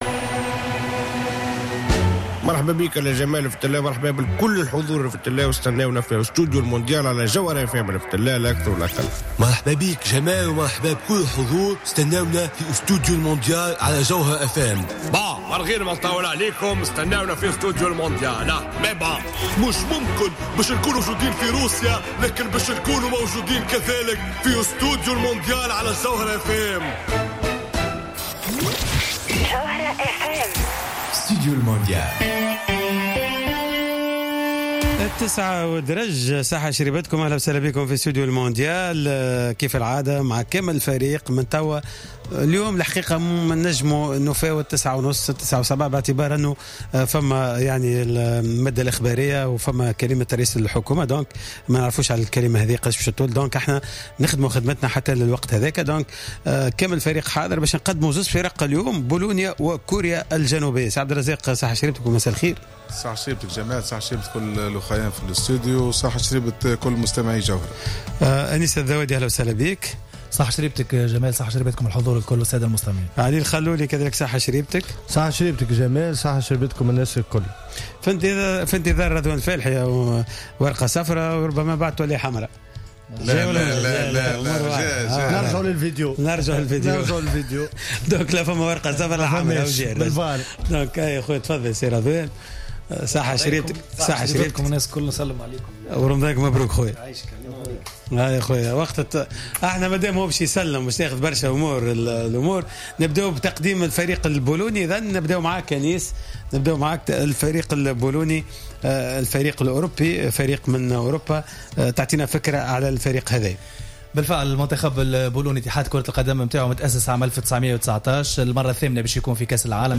من قبل المحللين الفنيين